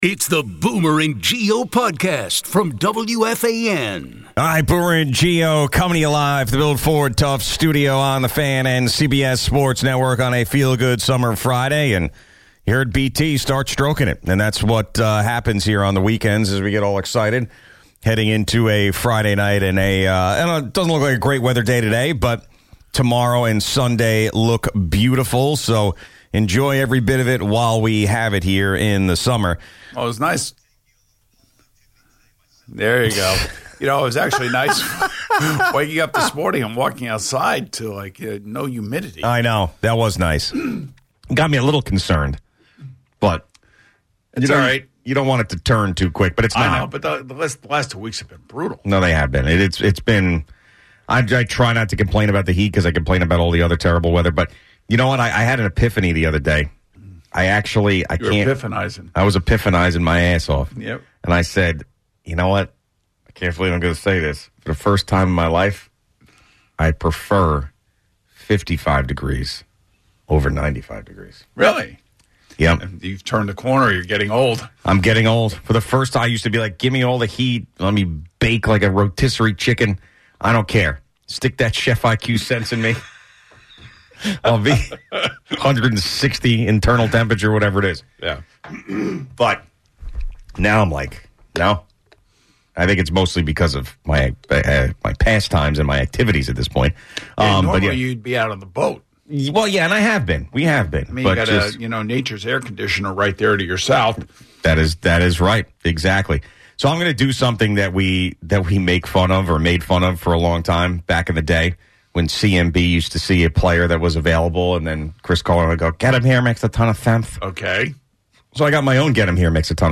A Jets fan rejects McLaurin, and a Giants fan notes Russell Wilson's impact on Gio.